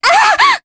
Mediana-Vox_Damage_jp_03.wav